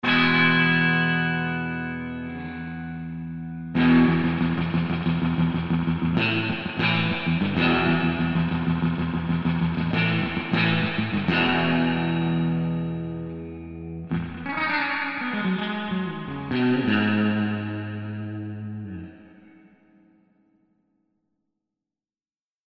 FENDER_SS_Bright.mp3